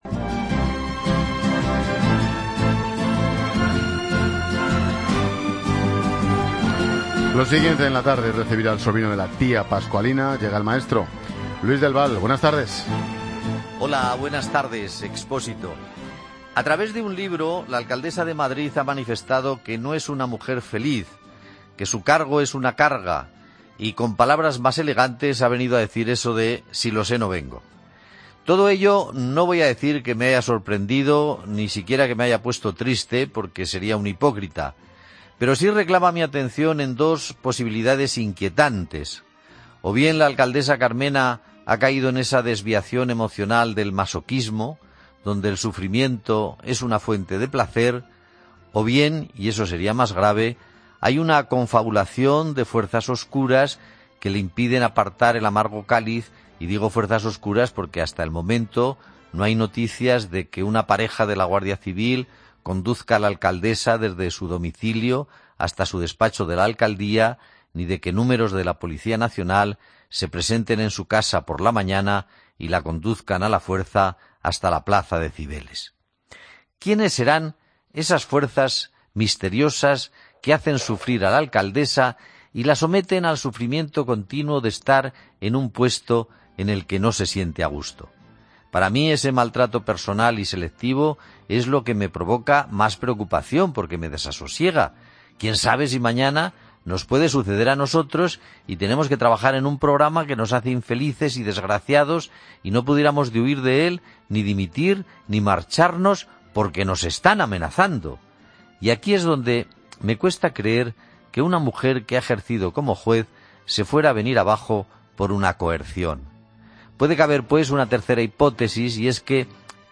Luis Del Val dedica su comentario a la alcaldesa de Madrid, Manuela Carmena, y a sus últimas declaraciones en las que admite no encontrarse contenta con su trabajo.